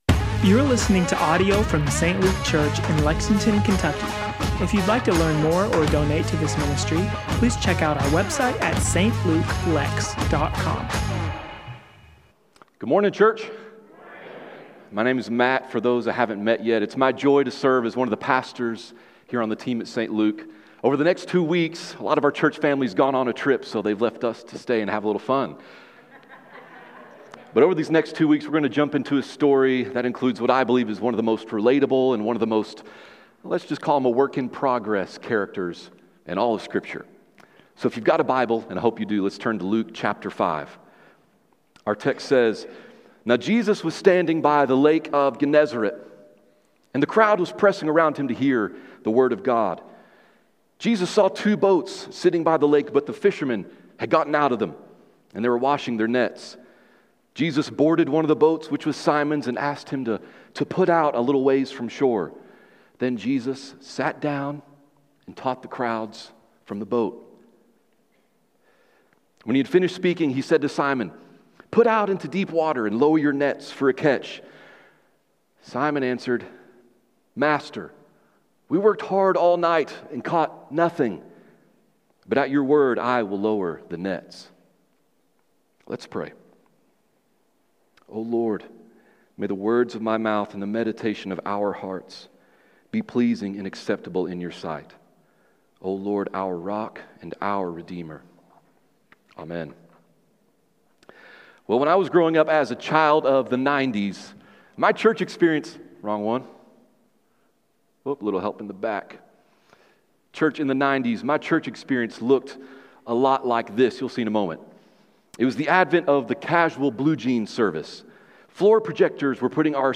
Sermons & Teachings